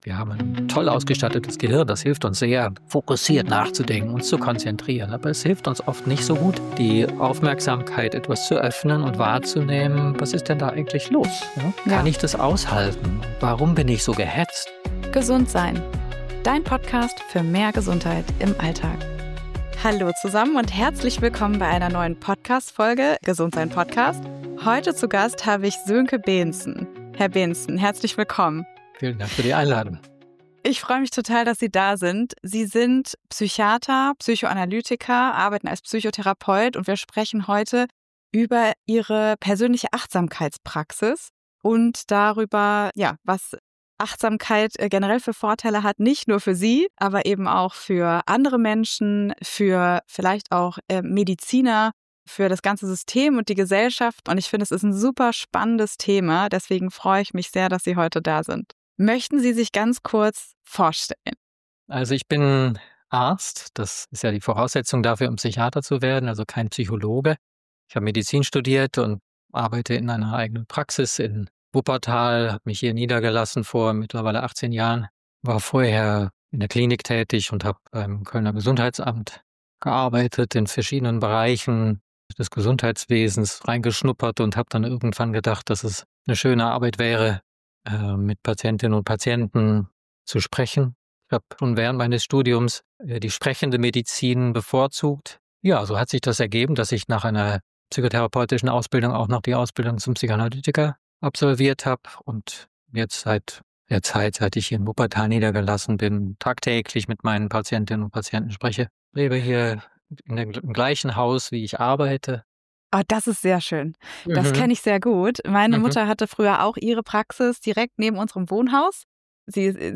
Er erklärt dir, wie die Praxis der Achtsamkeit dir helfen kann, die unbewussten Schleifen deiner Emotionen zu durchbrechen und dadurch ein tieferes Verständnis für dein wahres Wohlbefinden zu entwickeln. Erfahre, was Präsenz in der Begegnung mit anderen ausmacht und wie du lernen kannst, dir selbst mit mehr Mitgefühl zu begegnen. Ein spannendes Gespräch, das deinen Blick auf deine eigene innere Welt weitet.